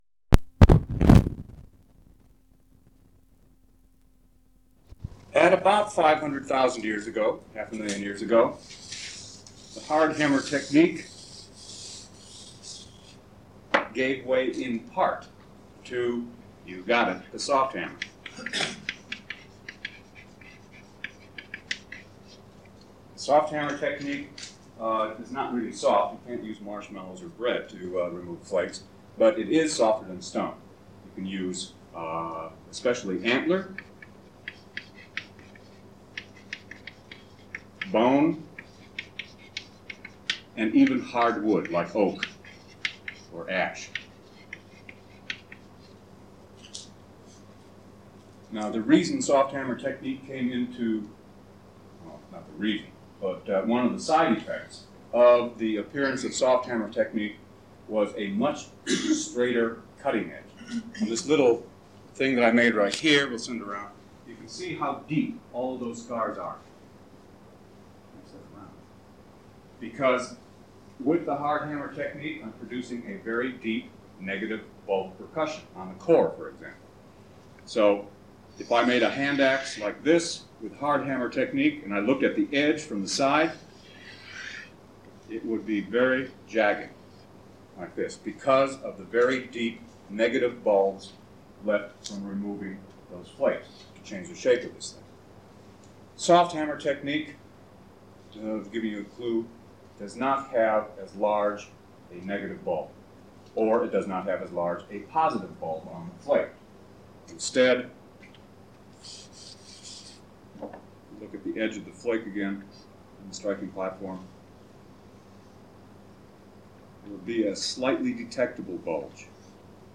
Stone Age of Jordan, Lecture 2, part 2: Lithic Technology
Stone_Age_Lecture_02_part_2_access.mp3